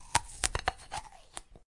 咬着苹果
描述：被咬的苹果：嘎吱嘎吱的声音
Tag: 用力咀嚼 食品 紧缩 苹果 OWI 零食